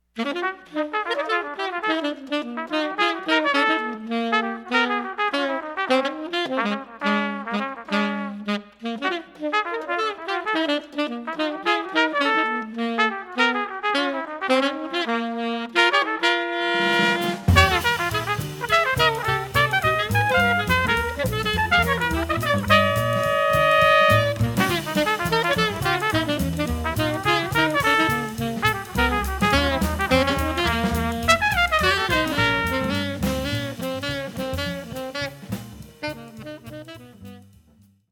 tenor saxophone